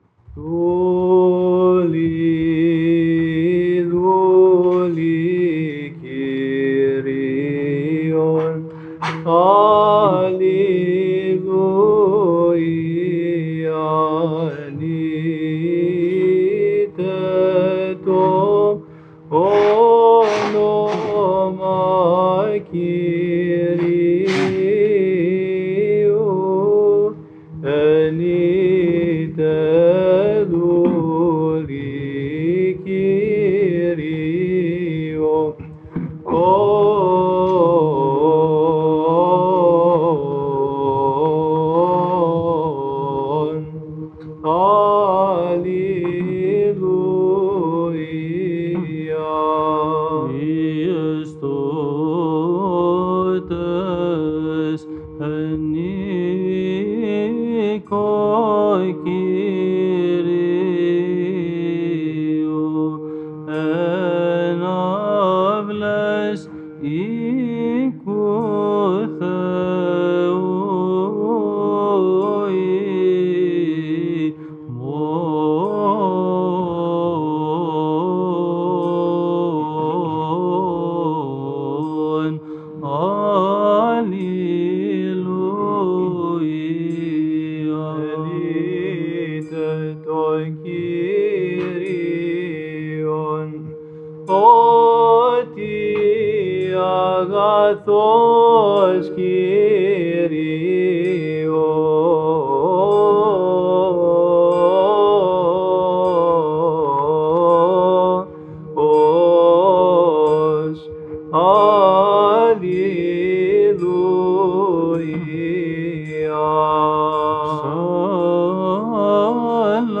Chanting the Polyeleos. Vigil of the Feast of Saint John of San Francisco, 2019
The Polyeleos chanted at Saint John of San Francisco Orthodox Monastery, 2019 Chanting the Polyeleos.
friday-vigil-stjohnfeast-2019-polyeleos-8.mp3